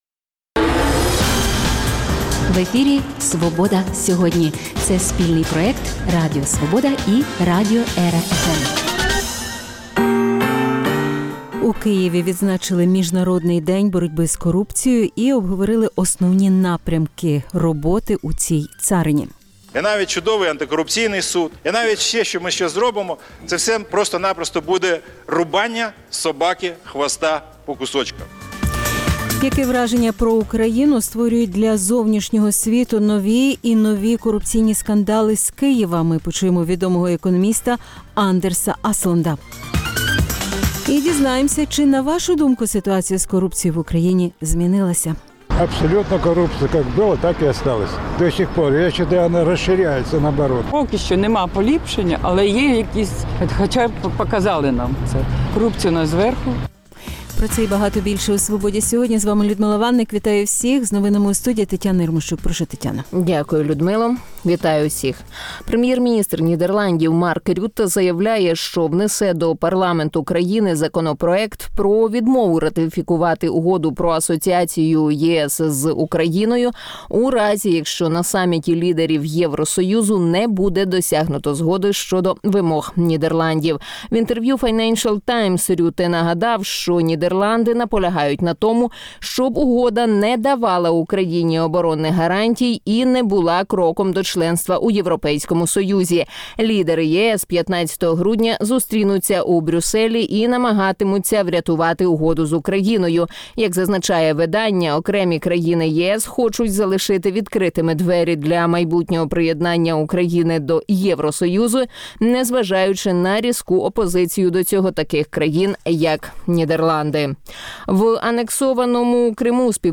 Почуємо відомого економіста Андерса Аслунда А чи, на вашу думку, ситуація з корупцією в Україні змінилася?